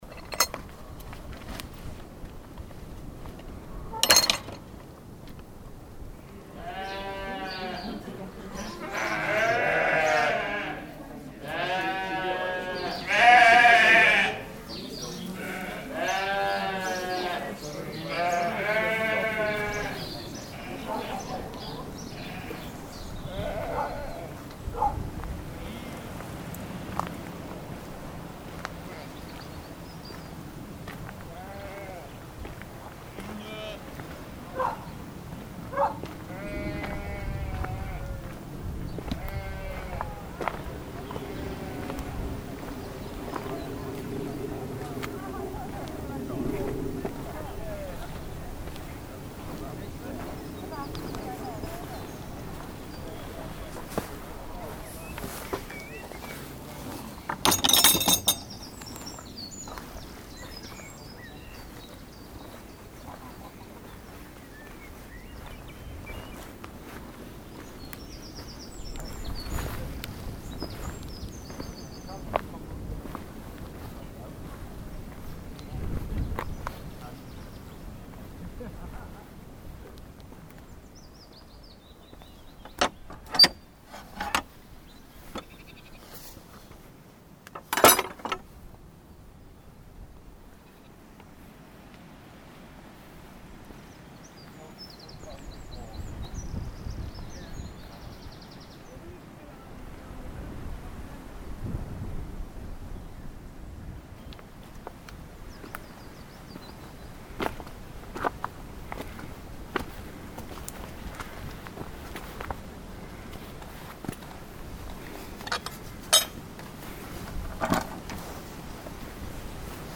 This is followed by sheep but the gates keep making their audio appearance throughout and after the sheep the aural textures open out like the salt marsh and the wind makes an appearance as it blows across the coarse herbs and grasses of the open landscape.
And those sheep had such strong voices! Great to hear your footsteps, the bird song, and the strong wind.
North-Gower-Walk-Soundscape.mp3